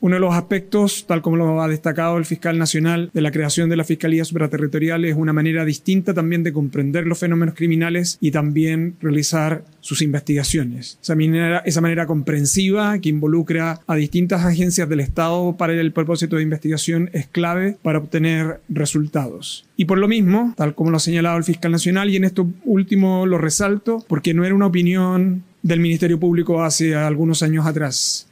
Por su parte, el ministro de Seguridad Pública, Luis Cordero, también se refirió a la promulgación de este nueva Ley.